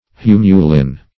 Humulin \Hu"mu*lin\, n. [NL.